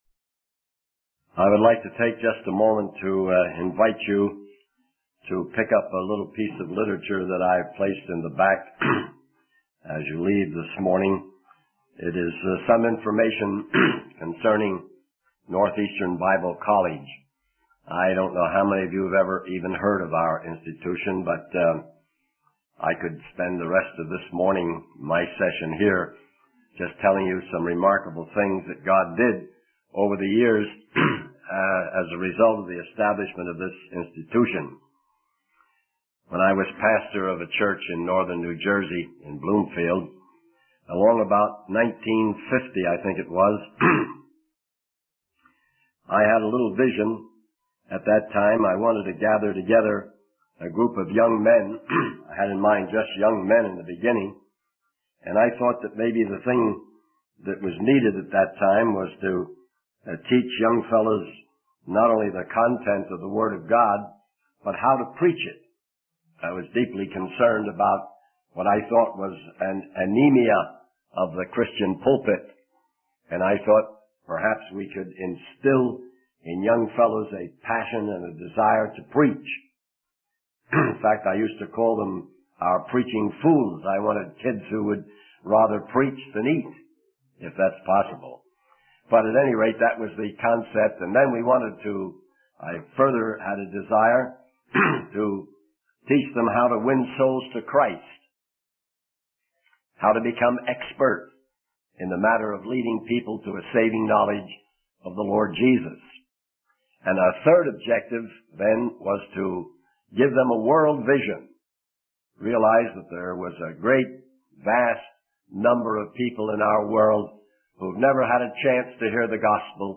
He begins by recounting a summer where children interacted with hippies and were promised to be featured in a movie. The speaker then transitions to discussing the letter of Peter in the Bible, emphasizing that believers are exactly where God wants them to be. He also mentions the importance of having a global perspective and reaching out to those who have not heard the gospel.